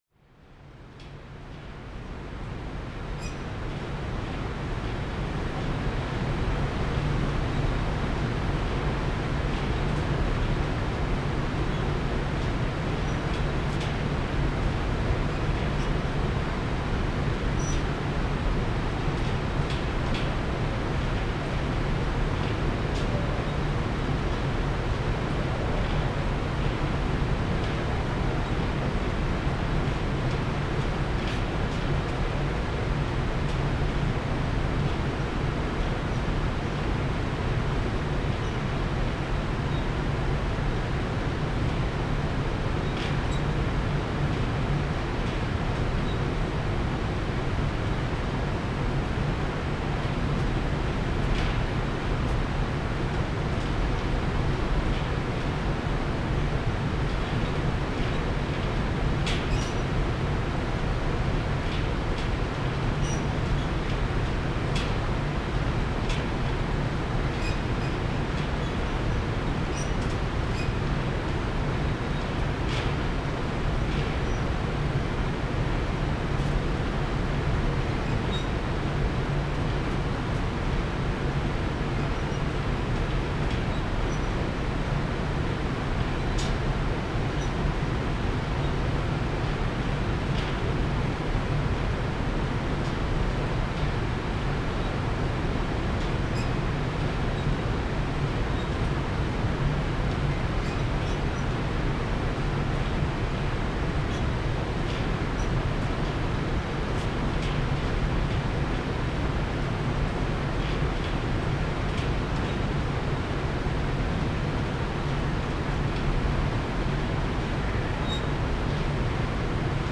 AMB_Scene04_Ambience_RS.ogg